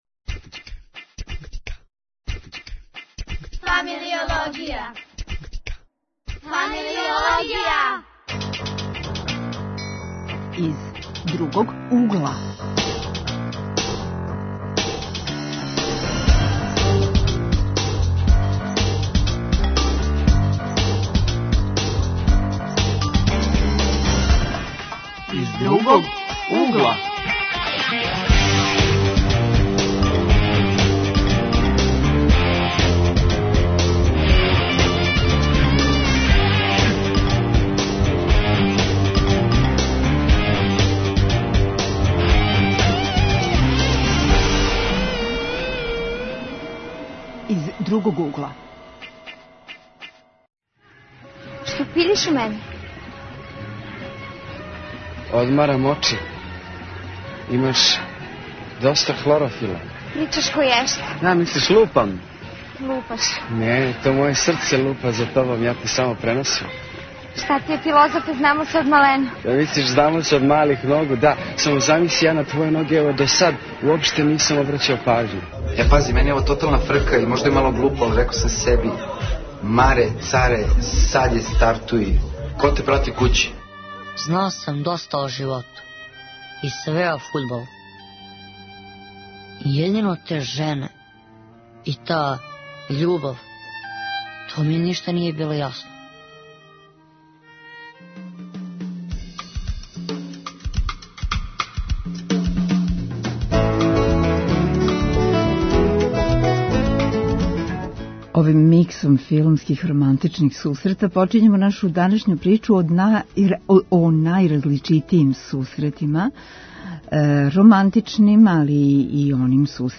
Из романтичног, енциклопедијског, културолошког, историјског, научног ...и разноразних других углова. Гости су студенти